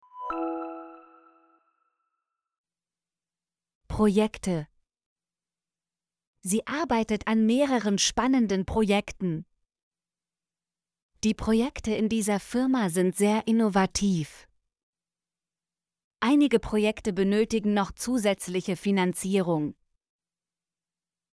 projekte - Example Sentences & Pronunciation, German Frequency List